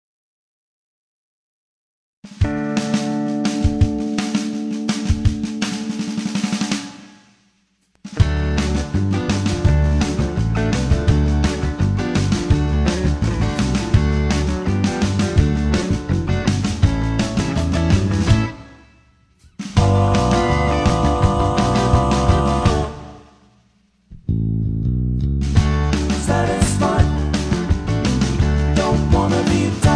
backing tracks
rock and roll